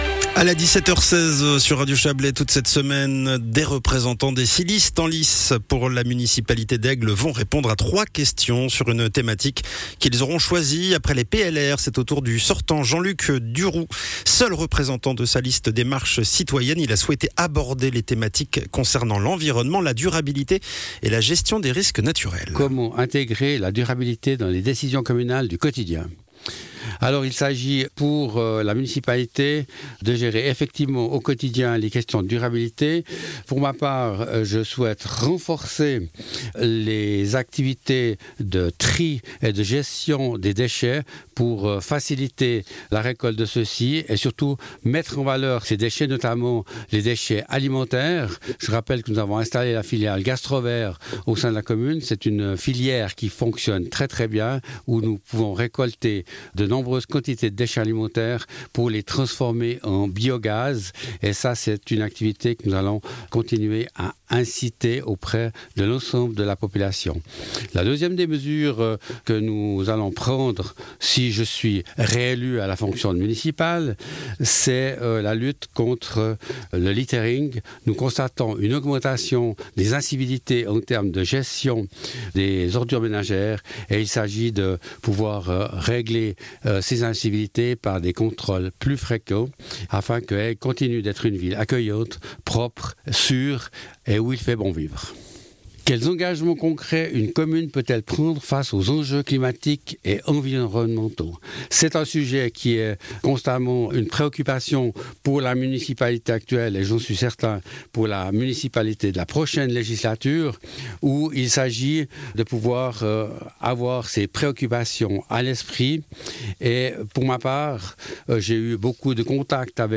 Intervenant(e) : Jean-Luc Duroux, municipal sortant de la liste Démarche Citoyenne